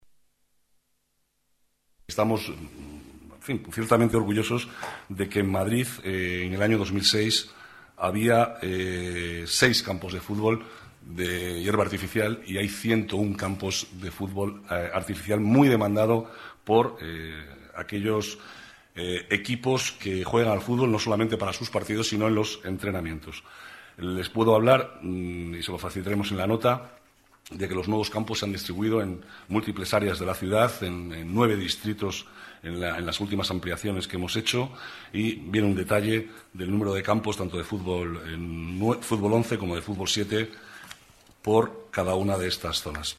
Nueva ventana:Declaraciones vicealcalde, Manuel Cobo: campos de fútbol con césped artificial